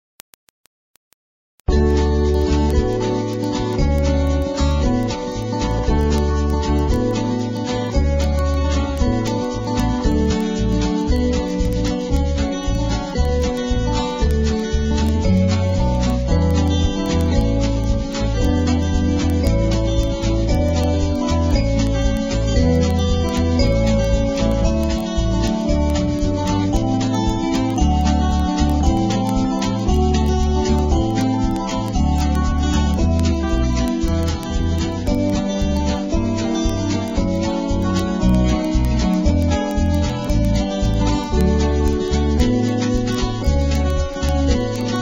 NOTE: Background Tracks 11 Thru 20